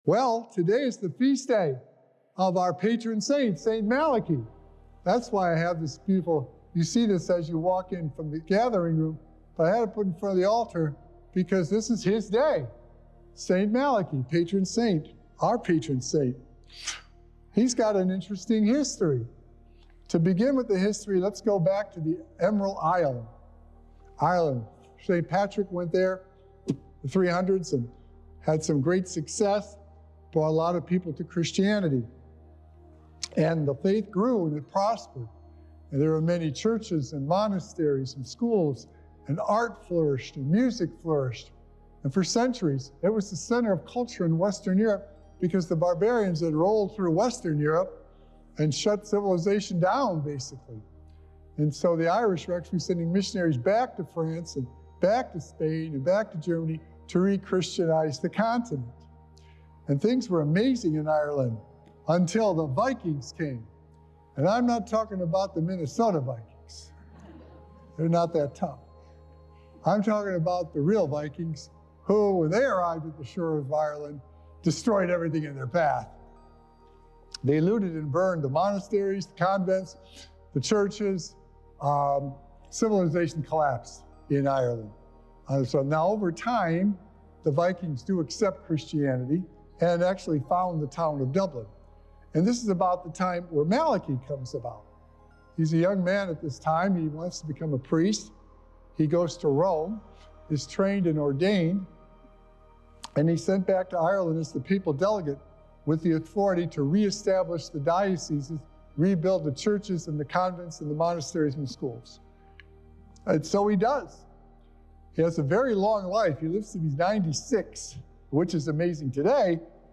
Sacred Echoes - Weekly Homilies Revealed
Recorded Live on Sunday, November 3rd at St. Malachy Catholic Church.